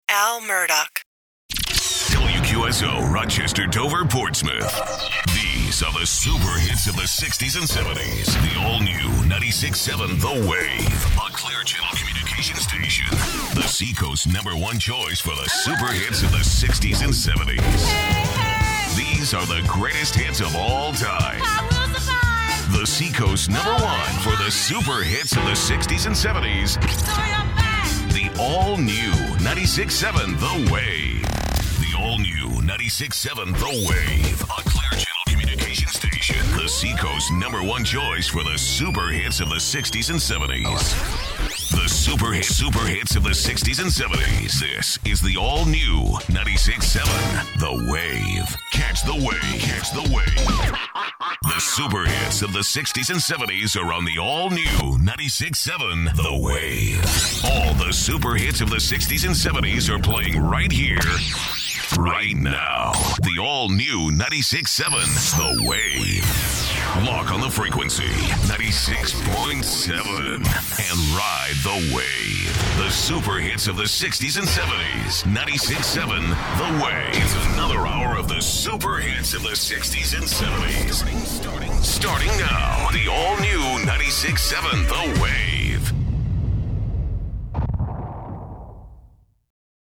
Oldies Demo
oldies.mp3